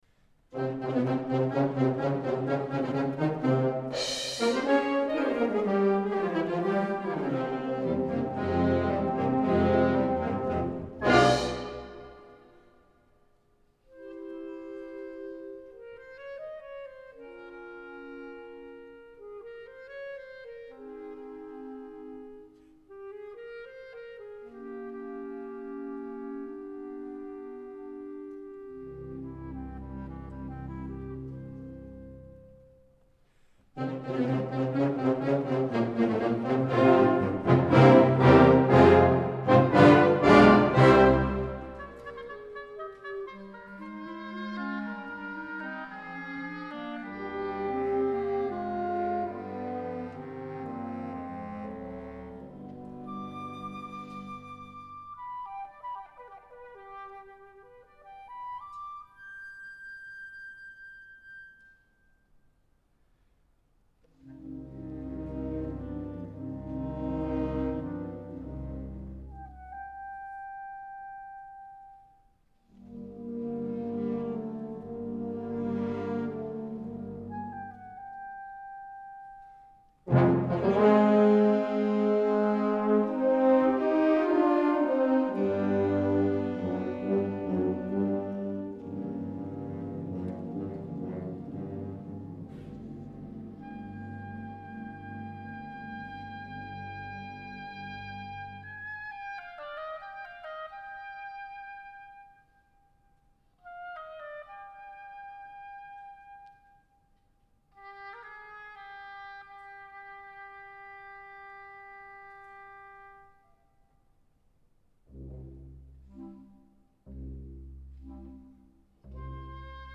Hörproben der Militärmusiken
"Der Zigeunerbaron - Ouvertüre" gespielt von der Militärmusik Vorarlberg